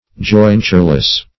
Jointureless \Join"ture*less\, a. Having no jointure.